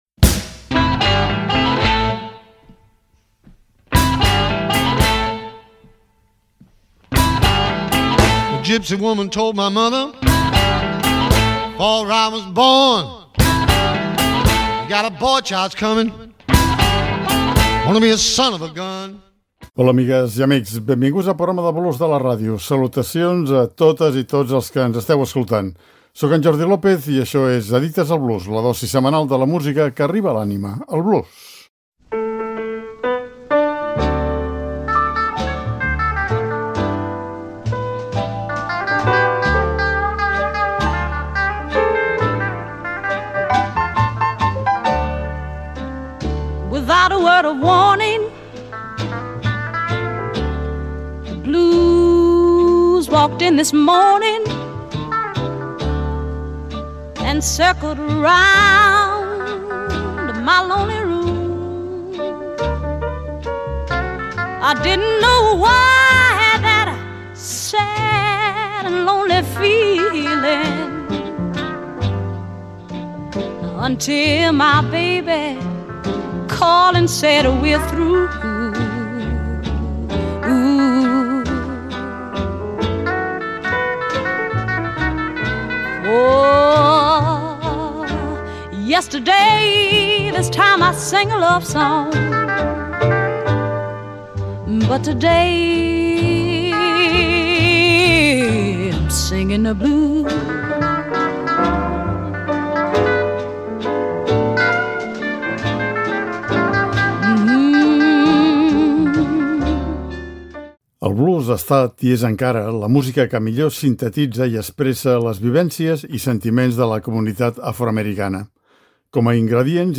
Addictes al Blues: Blueswomen, les dones del blues més actuals